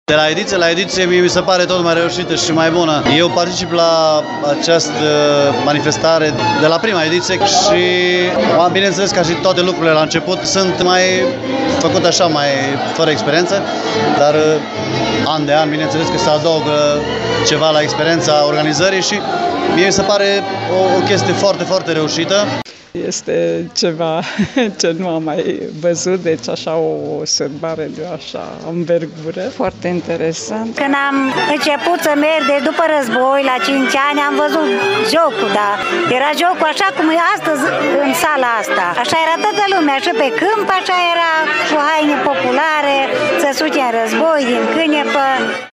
Balul Însuraților a ajuns la cea de-a IX-a ediție, iar în acest an s-a desfășurat în 6 și 7 februarie la Reghin.